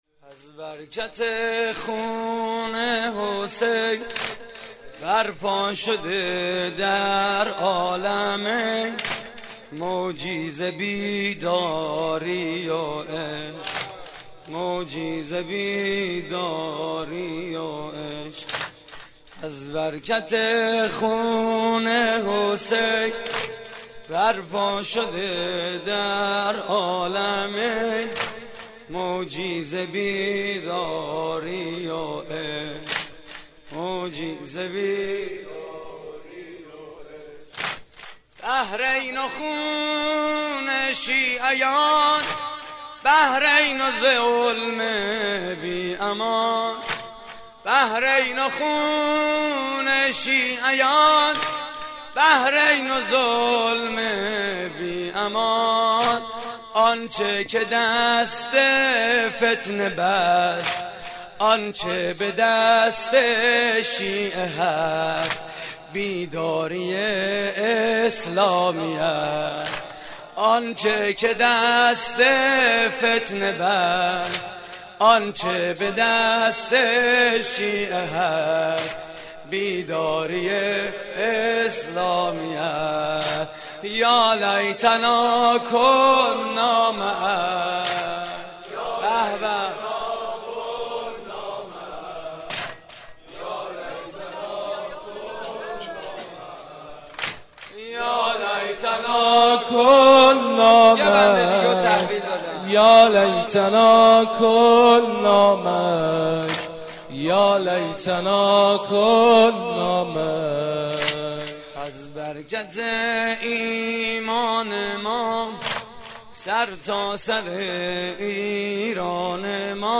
مراسم عزاداری شب تاسوعای حسینی